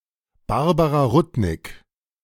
Barbara Rudnik (German: [ˈbaʁ.ba.ʁa ˈʁʊt.nɪk]
De-Barbara_Rudnik.ogg.mp3